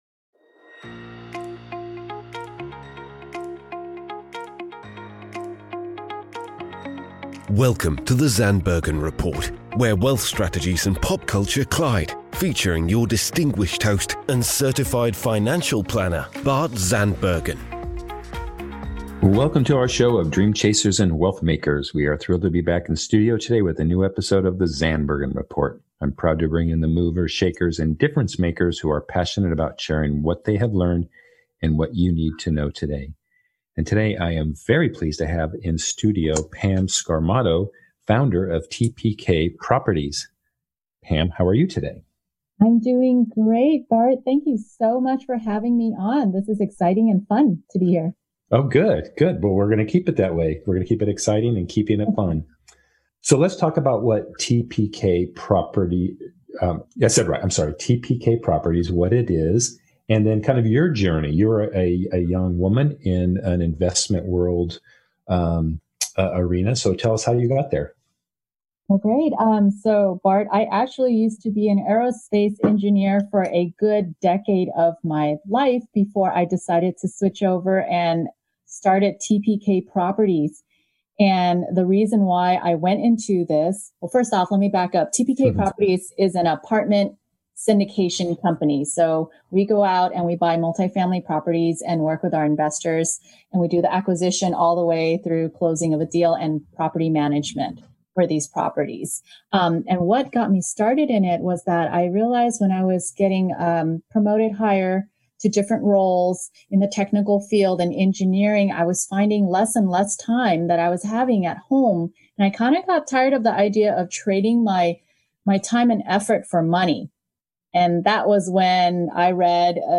was joined in the virtual studio